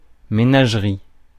Prononciation
Synonymes zoo jardin zoologique Prononciation France: IPA: [me.naʒ.ʁi] Le mot recherché trouvé avec ces langues de source: français Les traductions n’ont pas été trouvées pour la langue de destination choisie.